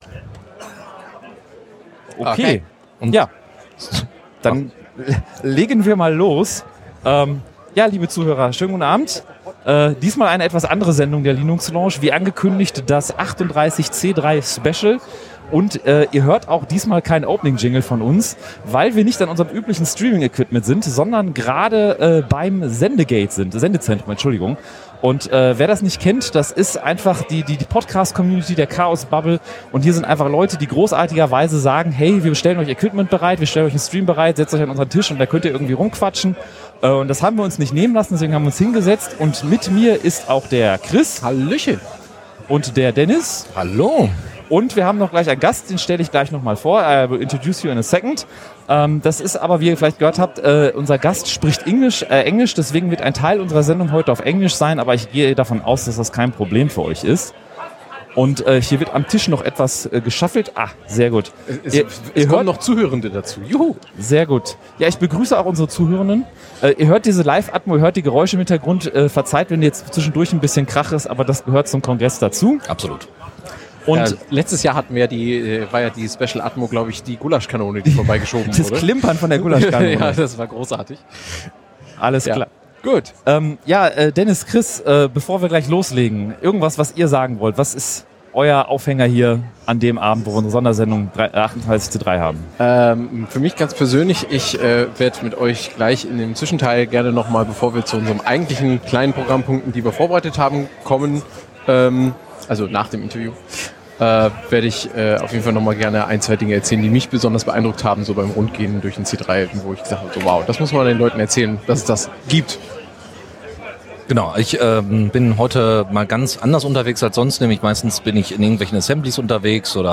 1 LL289 #38C3 Special - Mit I2P Interview